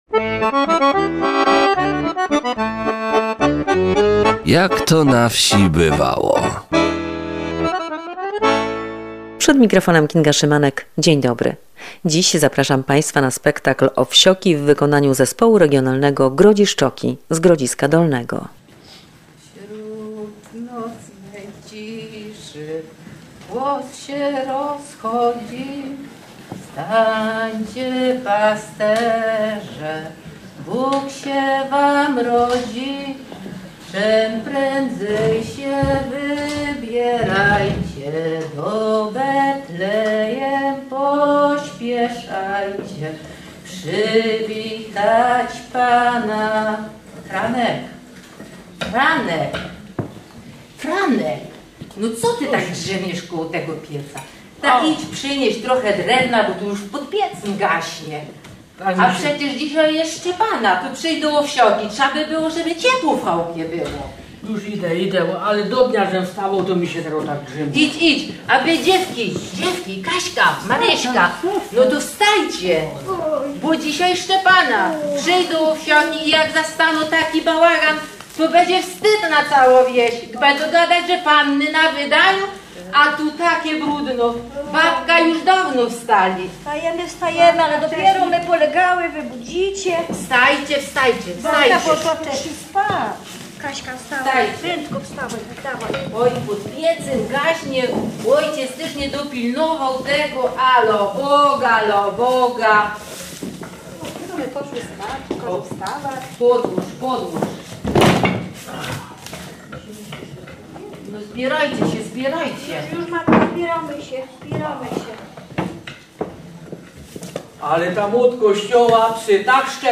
Spektakl "Owsioki" w wykonaniu Zespołu Regionalnego Grodziszczoki.